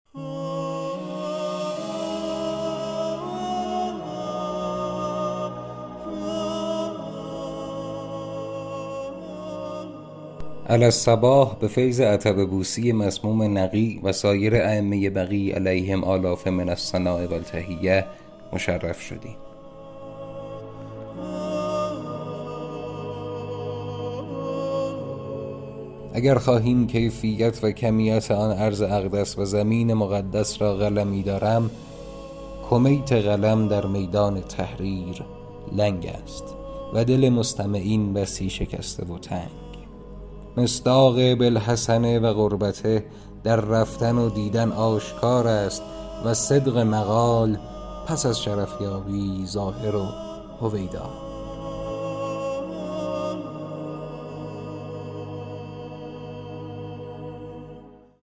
خبرگزاری ایکنا بر آن است تا در دهه اول ماه ذی الحجه با خوانش بخش‌هایی از سفرنامه‌های حج، مرهمی باشد بر زخم دل افرادی که از این سفر معنوی بازماندند.